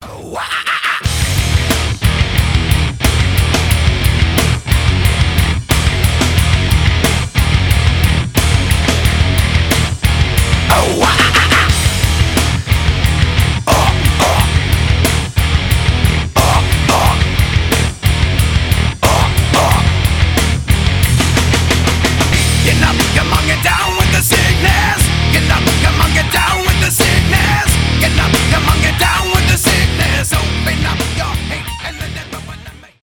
industrial metal
взрывные , мощные , nu metal
alternative metal